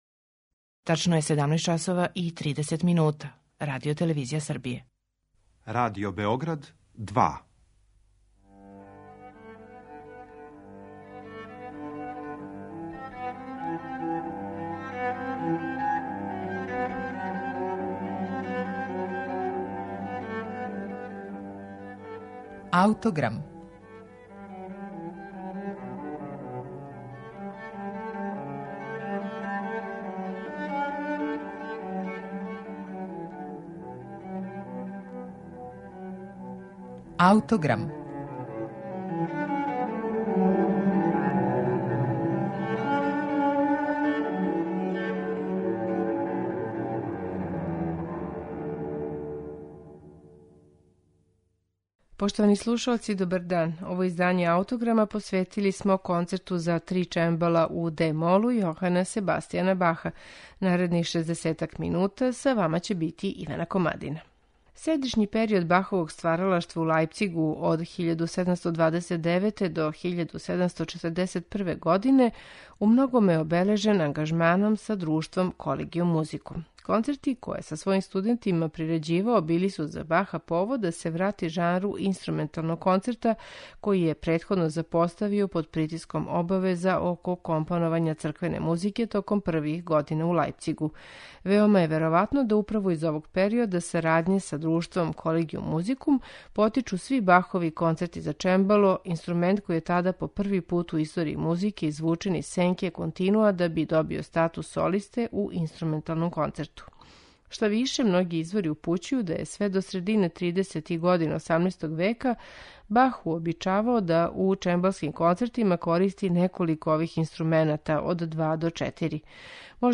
Бахов Концерт за три чембала